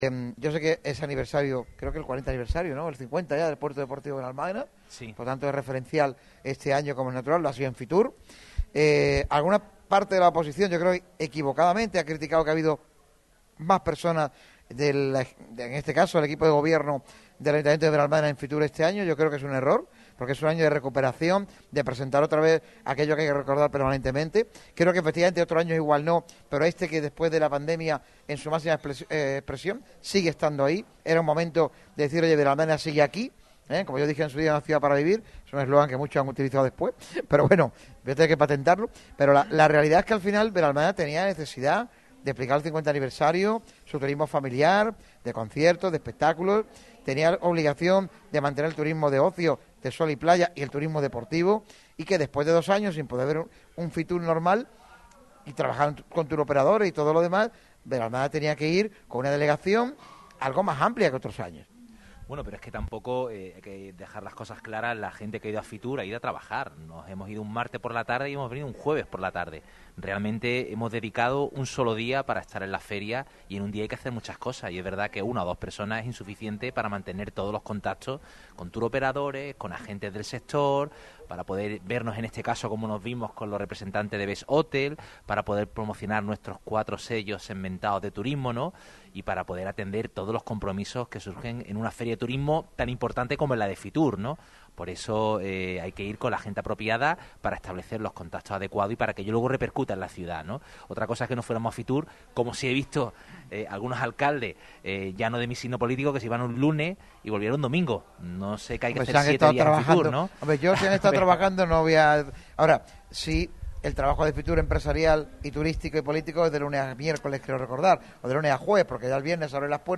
Víctor Navas, alcalde de Benalmádena, también da su punto de vista sobre el tema estrella de la semana: la aprobación polémica de la nueva Reforma Laboral.